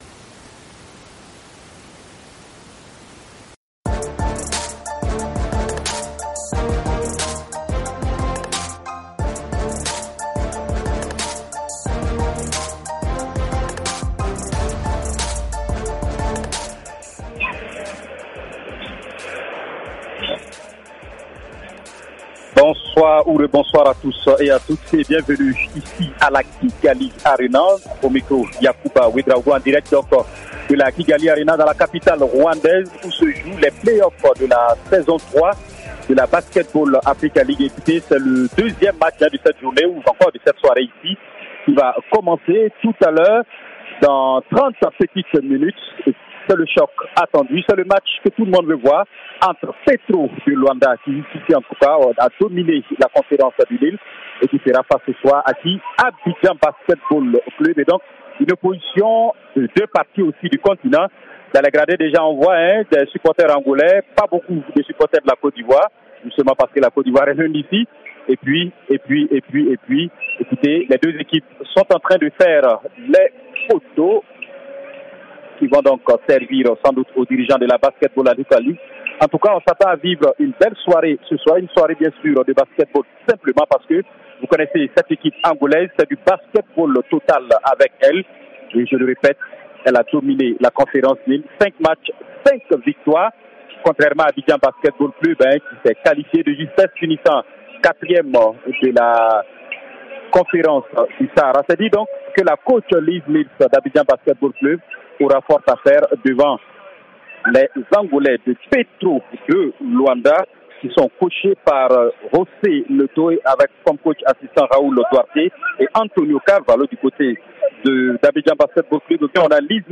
Basketball Africa League: en direct de Kigali